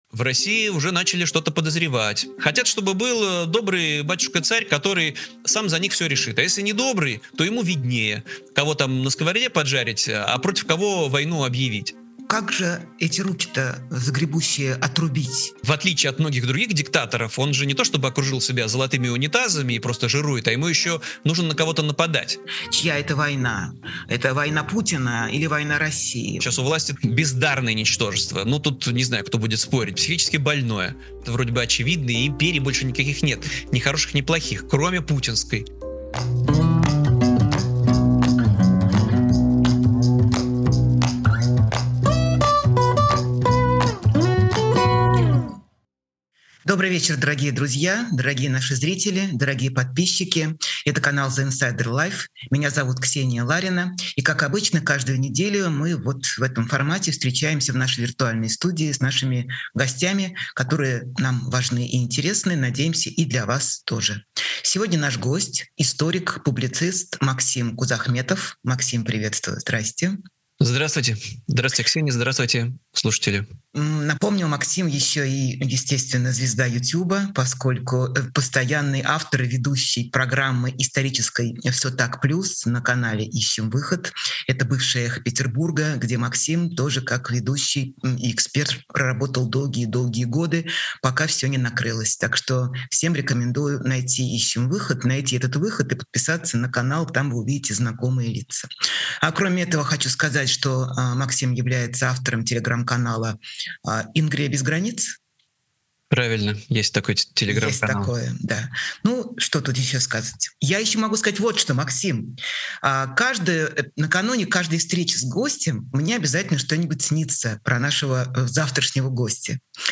Эфир ведёт Ксения Ларина
Новый выпуск программы «Честно говоря» с Ксенией Лариной.